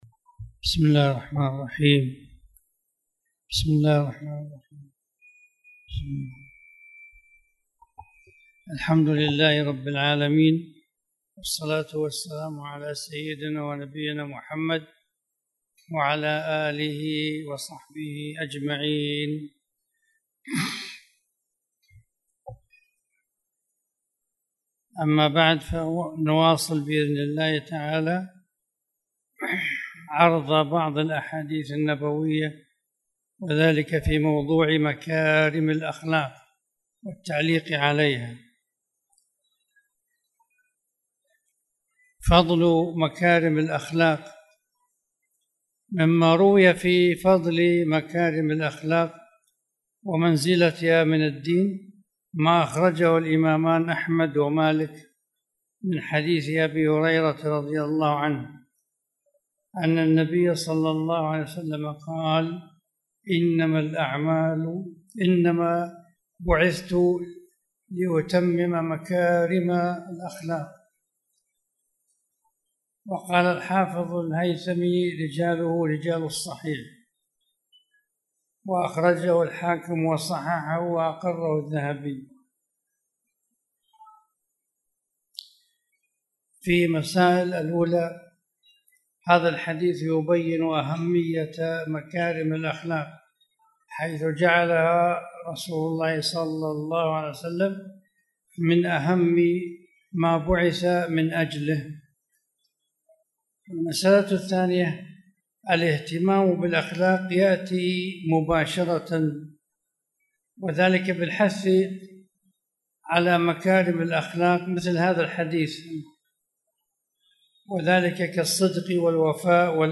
تاريخ النشر ٢٥ ربيع الأول ١٤٣٨ هـ المكان: المسجد الحرام الشيخ